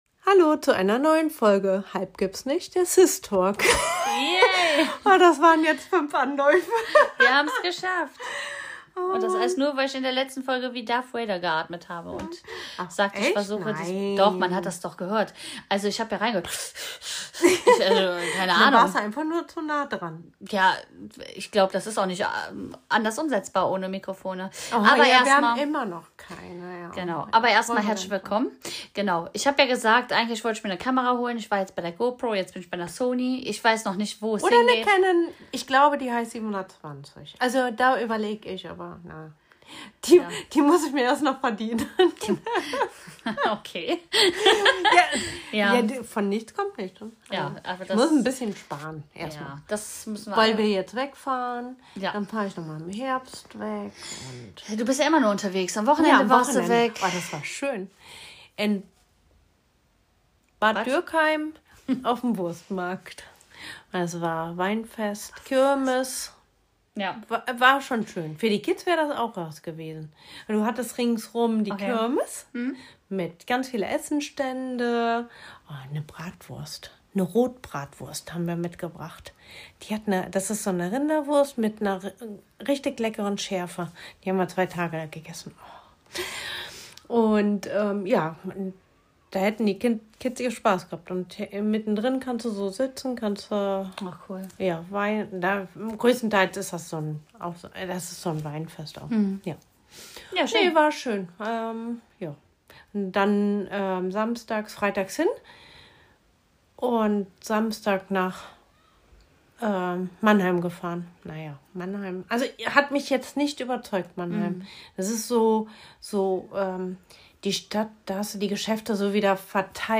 Mit viel Witz, aber auch einer ordentlichen Portion Aufregung, sprechen sie über die kleinen und großen Aufreger des Alltags – von nervigen Situationen bis hin zu Themen, die ihnen besonders unter den Nägeln brennen.
Dabei wechseln sich hitzige Diskussionen, humorvolle Seitenhiebe und persönliche Anekdoten ab, sodass es den Zuhörer:innen nie langweilig wird.